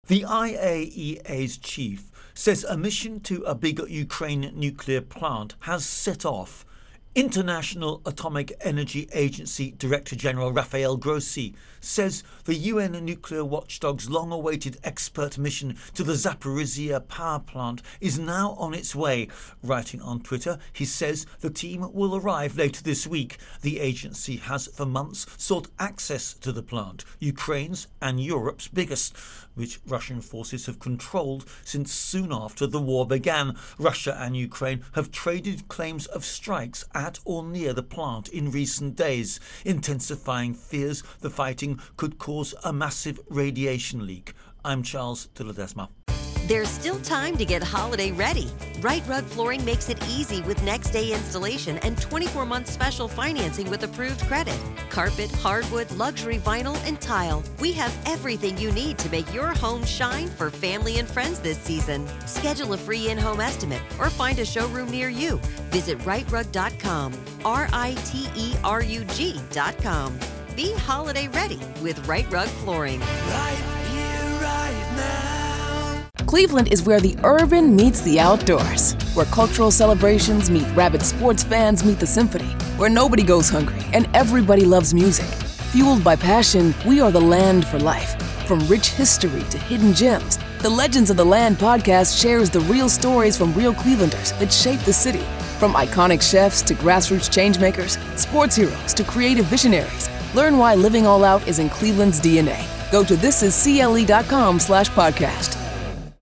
reports on Russia Ukraine War.